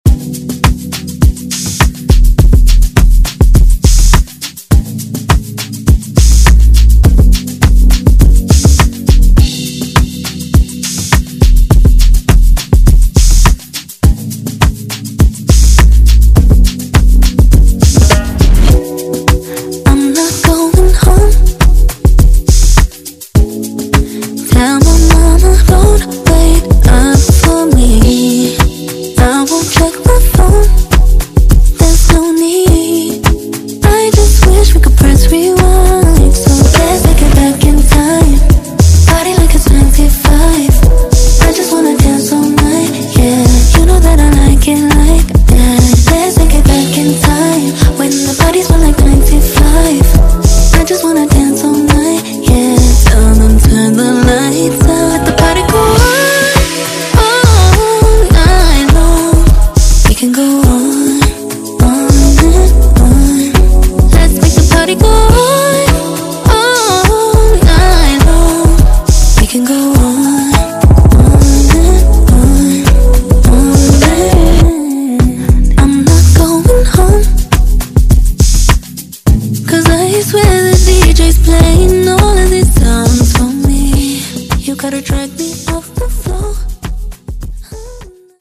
Genres: AFROBEAT , DANCE , RE-DRUM
Clean BPM: 124 Time